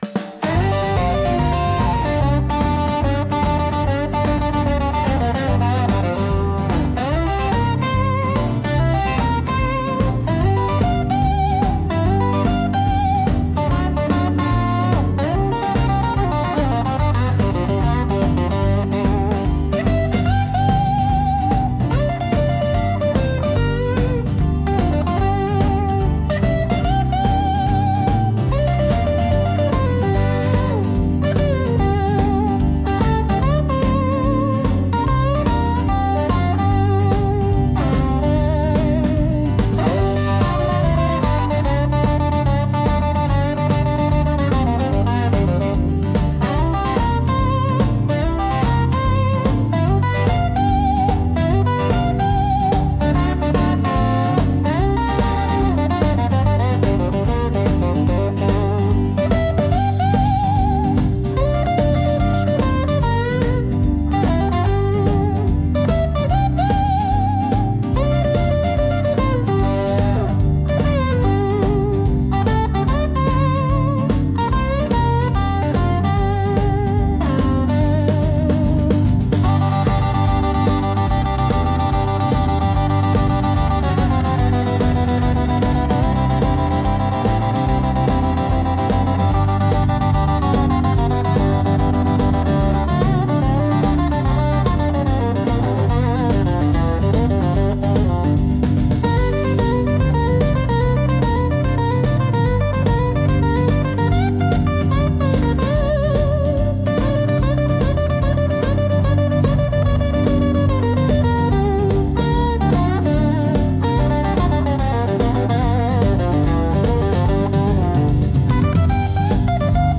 😅となりました💦 コメディかなんかで使えればどうぞ。